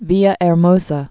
(vēə-ĕr-mōsə, bēyä-)